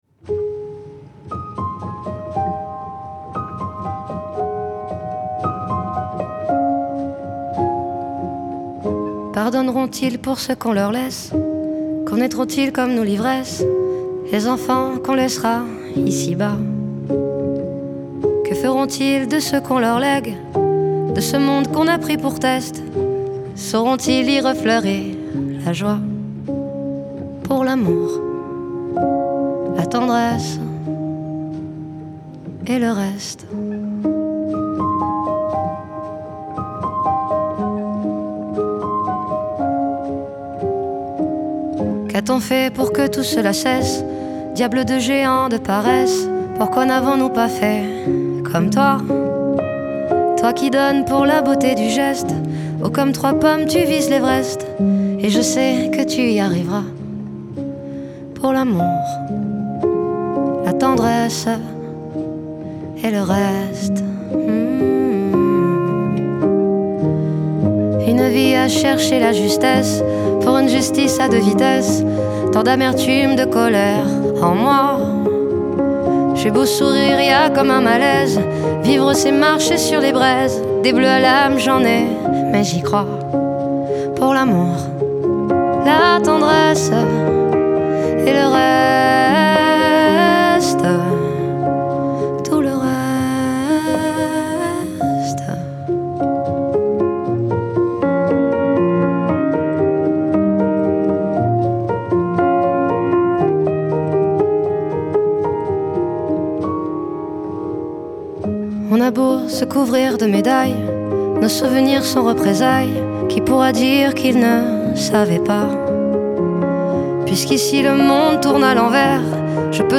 Genre : French Music